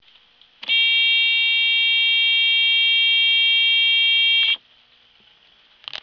Il progetto del cicalino dei fanali
Chiudendo la portiera il cicalino deve smettere di pigolare.
Ecco quà! E' un suono petulante ed irritantissimo